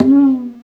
50 HI DRUM.wav